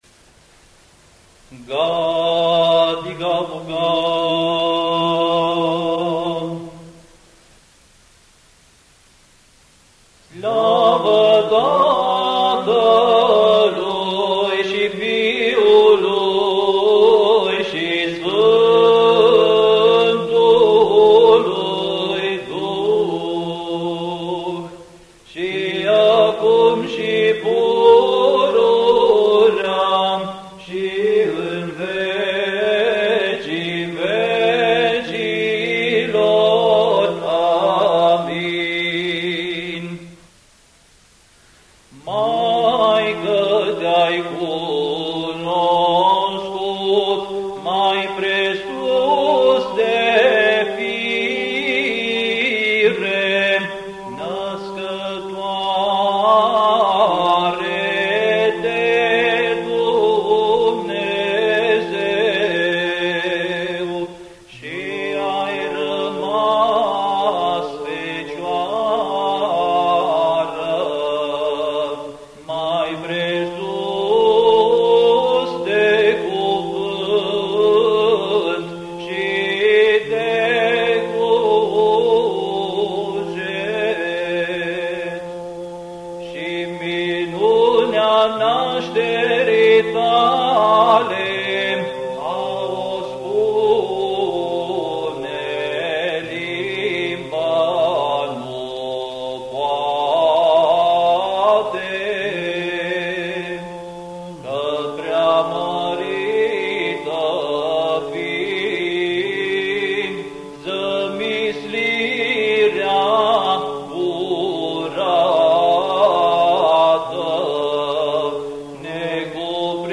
Index of /INVATAMANT/Facultate Teologie pastorala/Muzică bisericească și ritual/Dogmaticile
07. Dogmatica glasul 7.mp3